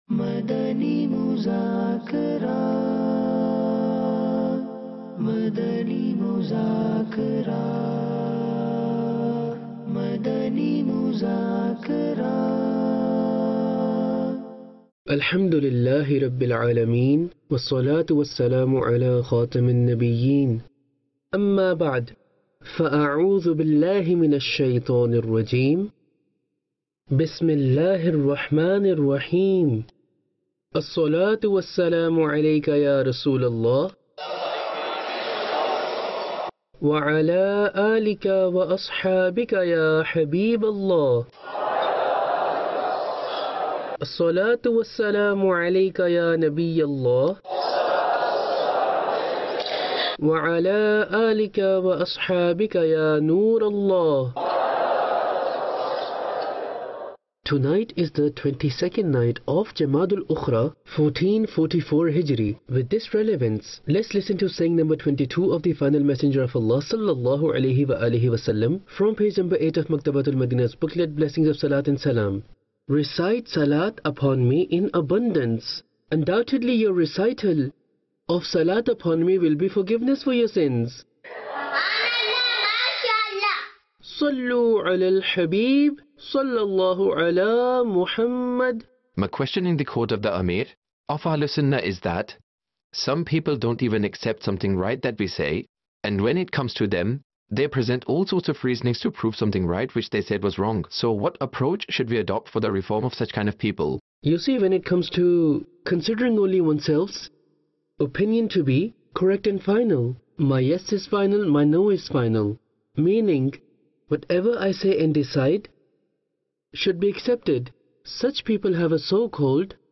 English Dubbed